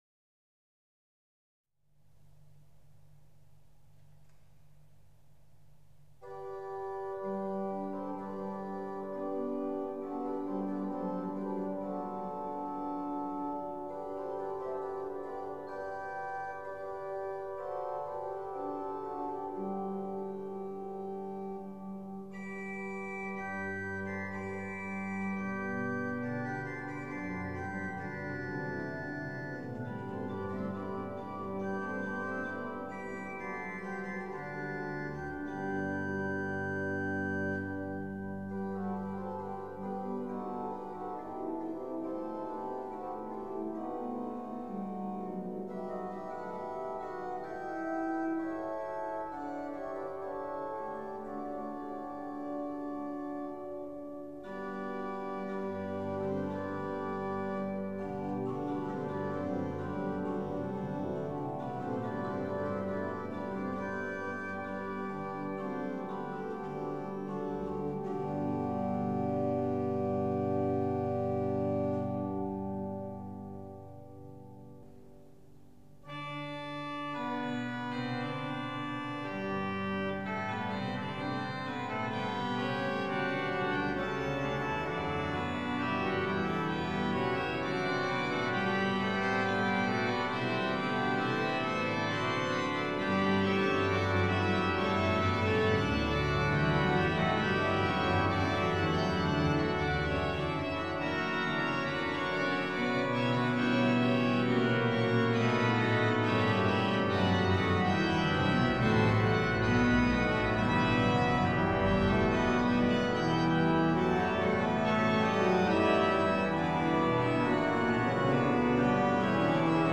auf der gro�en Klais-Orgel der Abteikirche Himmerod